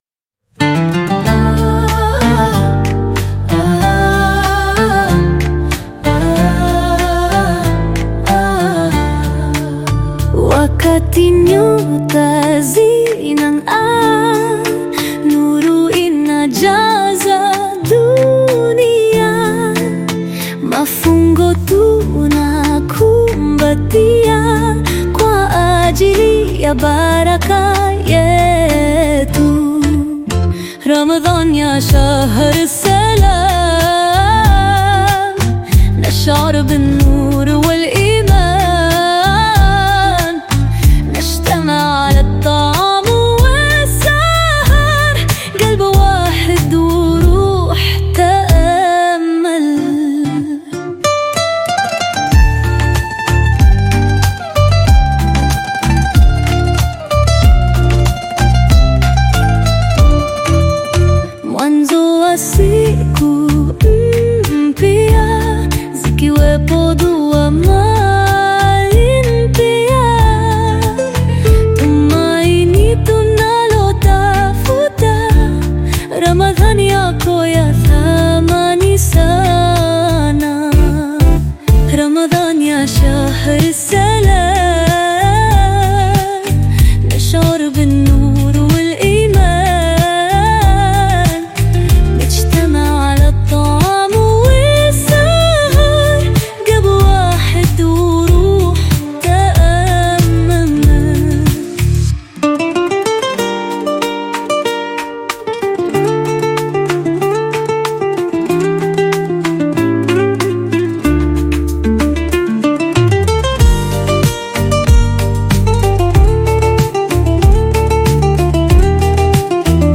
Qaswida song
Qaswida You may also like